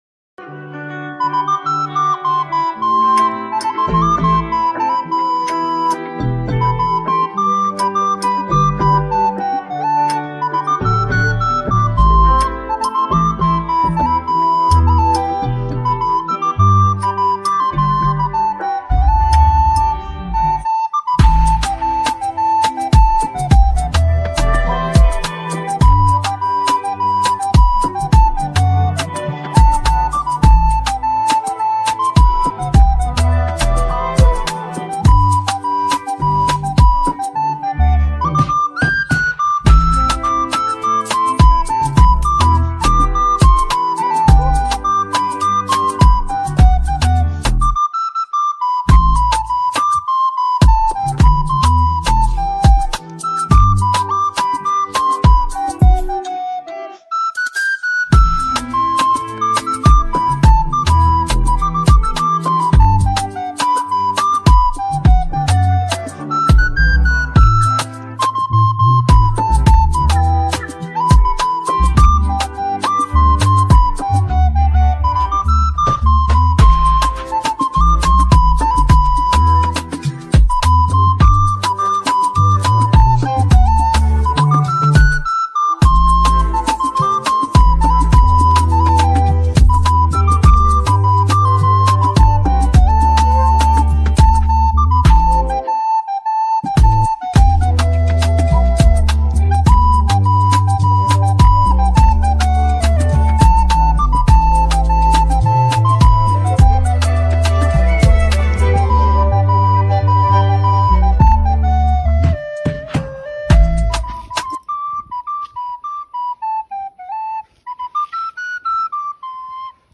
remix flûte